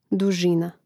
dužìna dužina